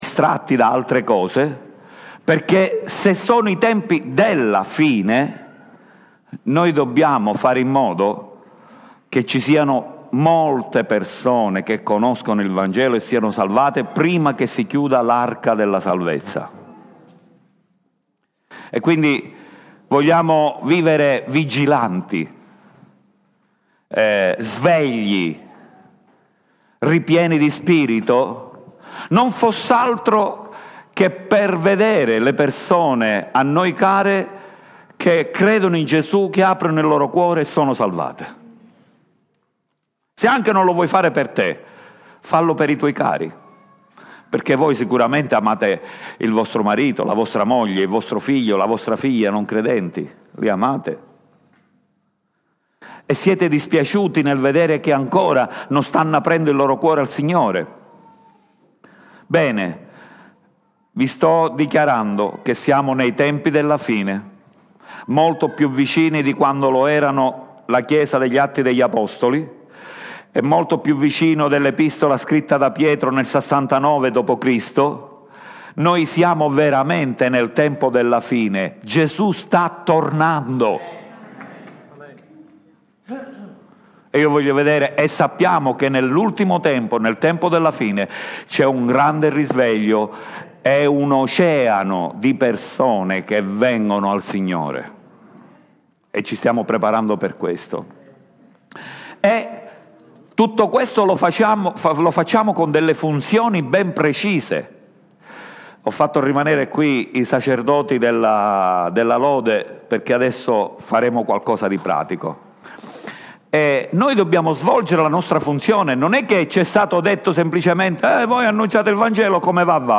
Riprendiamo il filo della predicazione di domenica scorsa e leggiamo ancora I Pietro 2:9-10